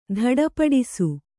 ♪ dhaḍa paḍisu